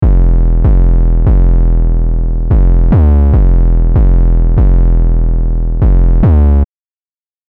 808 – Armour
808-Armour.mp3